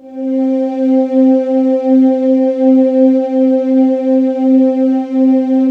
Index of /90_sSampleCDs/USB Soundscan vol.28 - Choir Acoustic & Synth [AKAI] 1CD/Partition D/11-VOICING